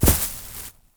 Footsteps / grass.wav
grass.wav